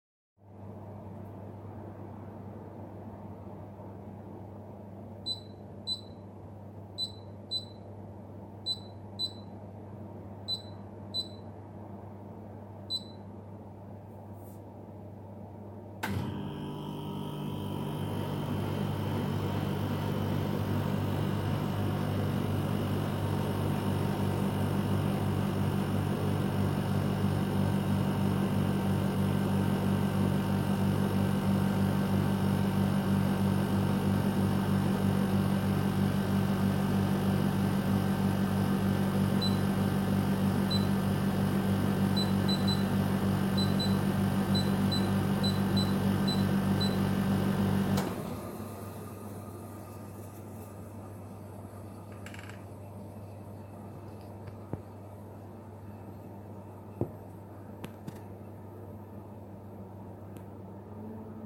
Question forum dépannage climatisation : Bruit compreseur climatiseur mobile Dolceclima silent 10
Les données sont bonnes il y a comme un bruit de claquement désagréable comme un vieux frigo...
Bonjour voici les fichiers demandées, début air au minimum puis déclenchement du compresseur puis retour normal..
Le bruit qui me dérange c'est bruit de claquement continu qui est omniprésent.